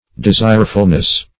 Search Result for " desirefulness" : The Collaborative International Dictionary of English v.0.48: Desirefulness \De*sire"ful*ness\, n. The state of being desireful; eagerness to obtain and possess.